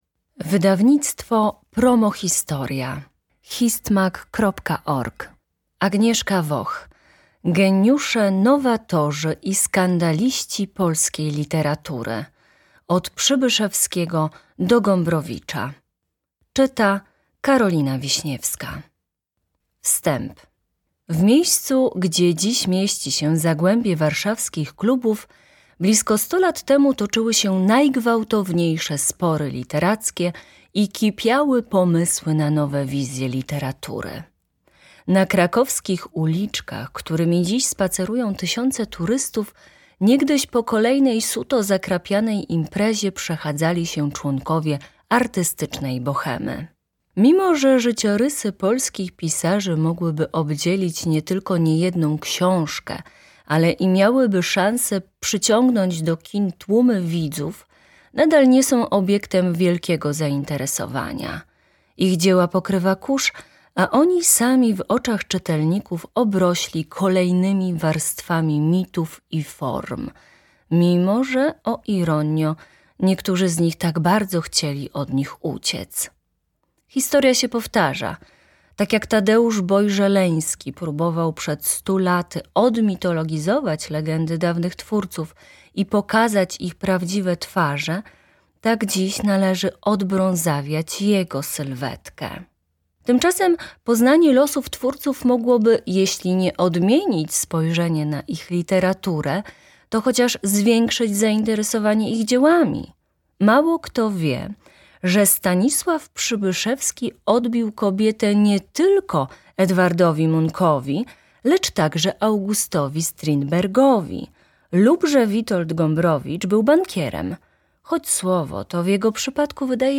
Posłuchaj fragmentu książki: MP3